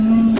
camera_out.wav